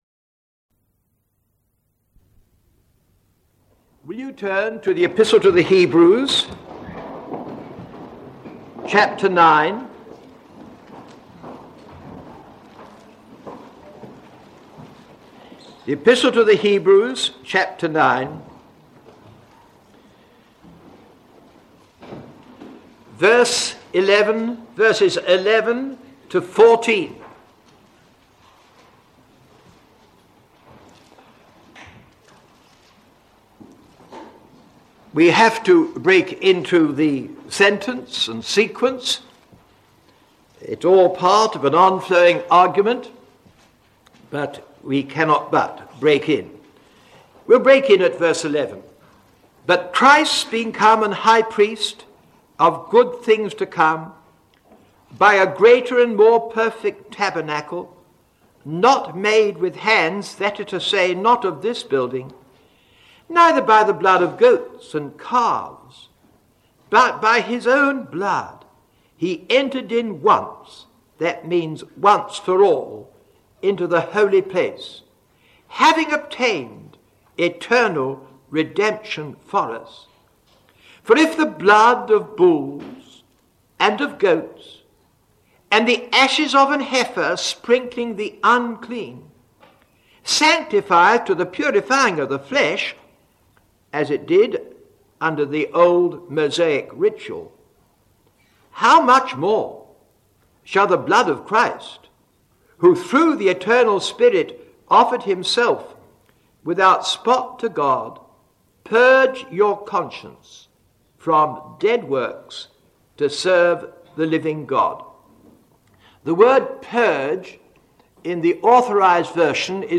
In this sermon, the speaker focuses on the importance of having a cleansed conscience through the blood of Christ.